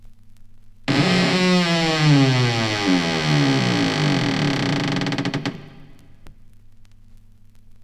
• creaky door closes.wav
creaky_door_closes_BoX.wav